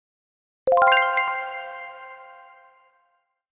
right answer sound effect